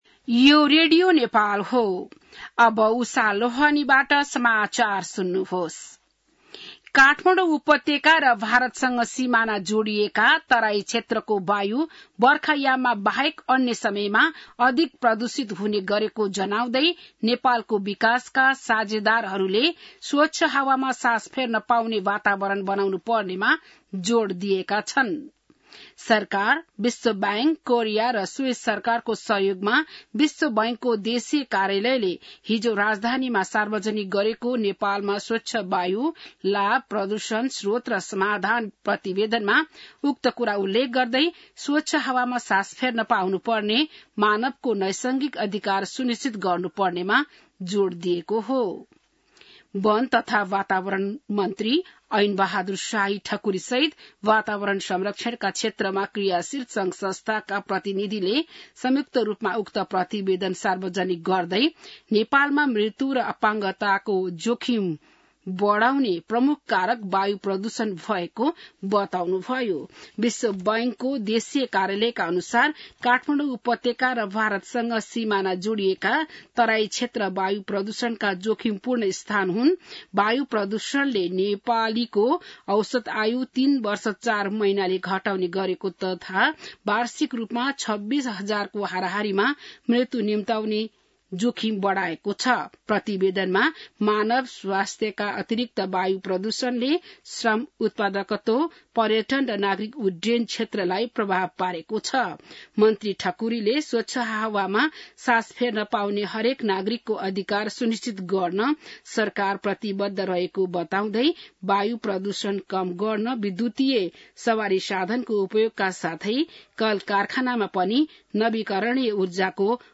बिहान १० बजेको नेपाली समाचार : ४ असार , २०८२